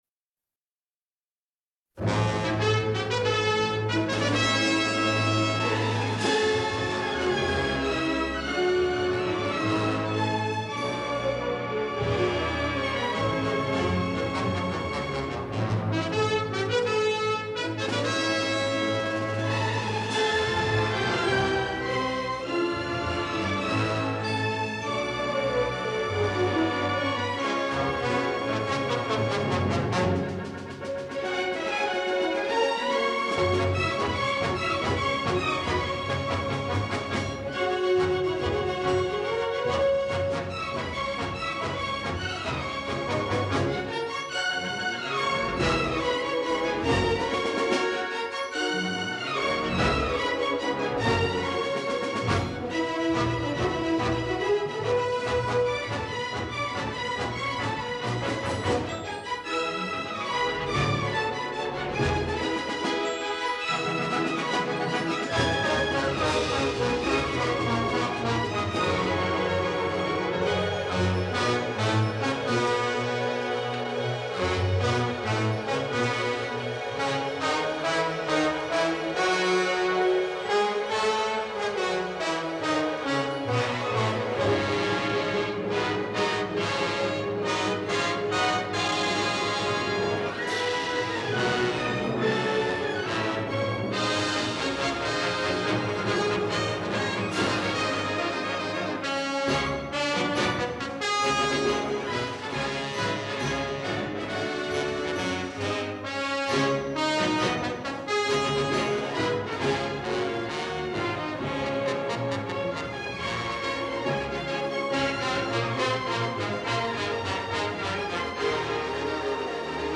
remorselessly up beat and loud